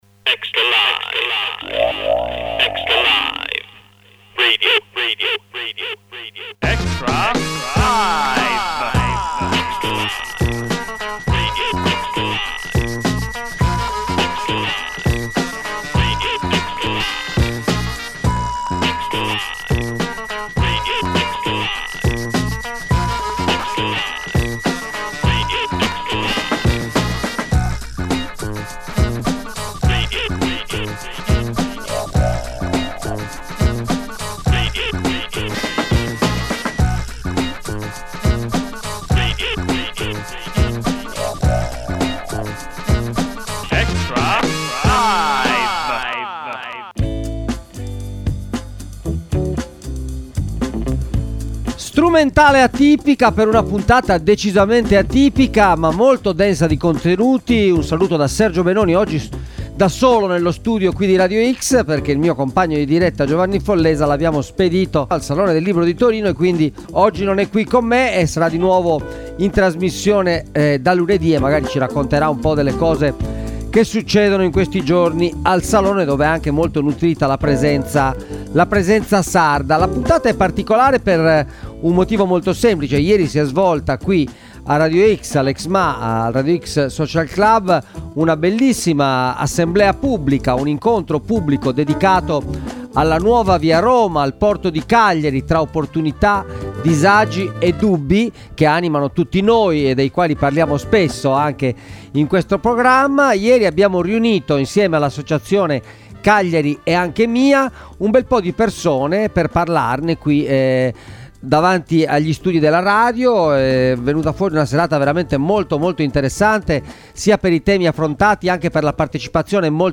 Extralive! Ogni mattina in diretta dalle 8 alle 9 e in replica dalle 13, il commento alle notizie di giornata dalle prime pagine dei quotidiani con approfondimenti e ospiti in studio.